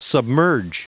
Prononciation du mot submerge en anglais (fichier audio)
Prononciation du mot : submerge